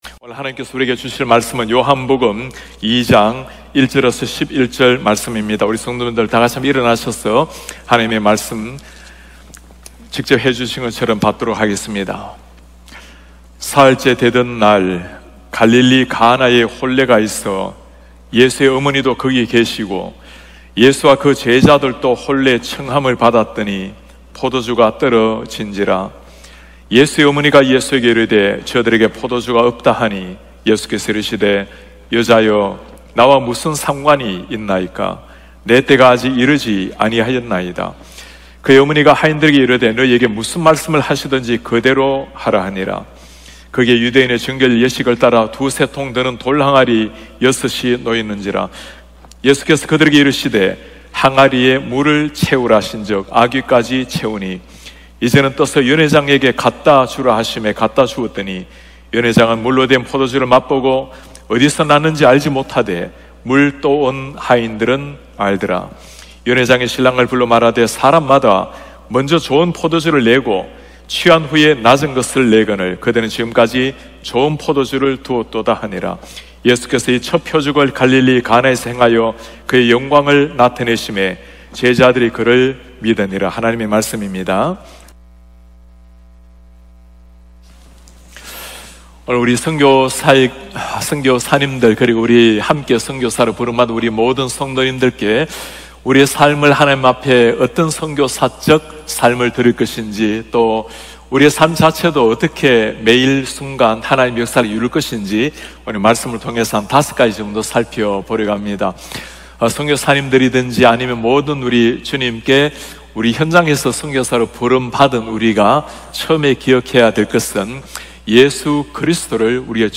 예배: 금요비상기도회